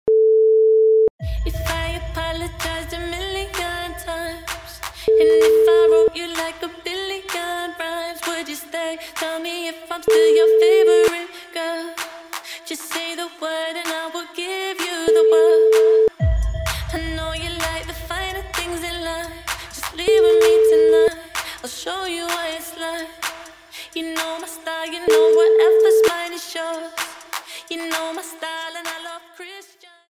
Easy Listening Dance